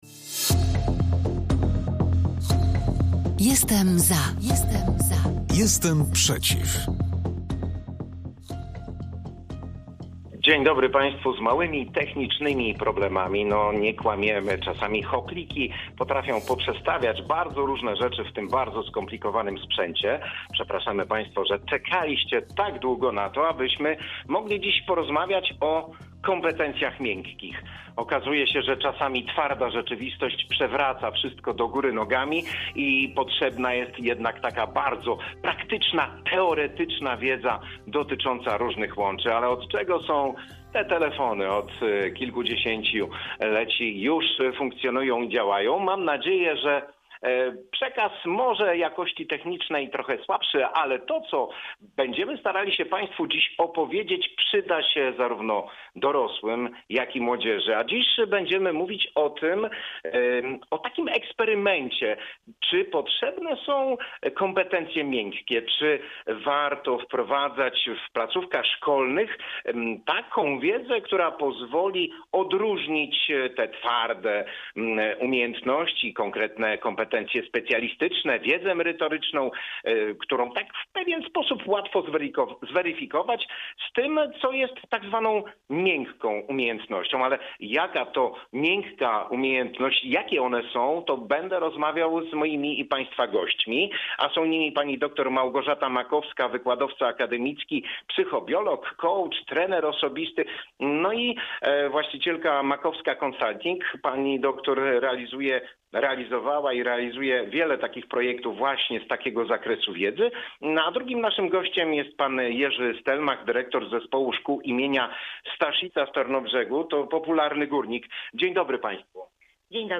audycji Polskiego Radia Rzeszów